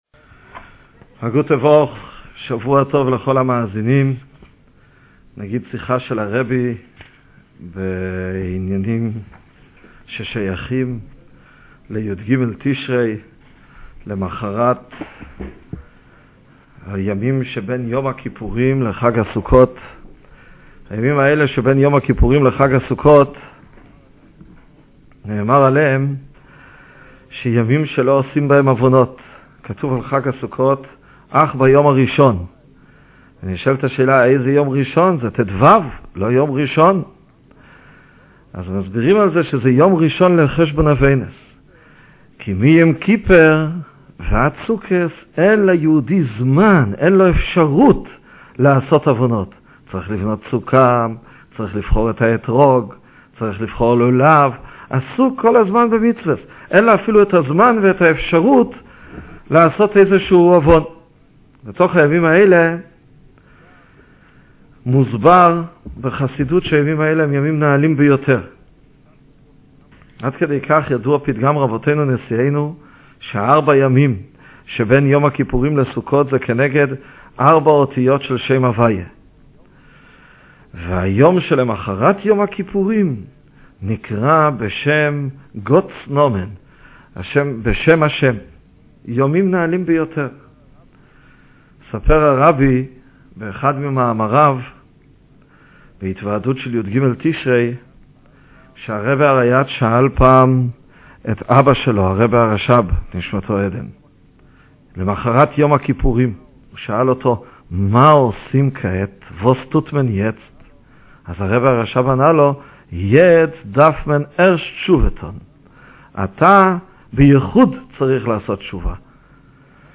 הקלטה מתכנית הרדיו 'קול-מבשר' משנת תשס"ב: שאלות לחג הסוכות ובעניני שנת "הקהל" להאזנה לחצו על כפתור ההפעלה, או באופצייה הנוספת: ● לא מצליחים להאזין?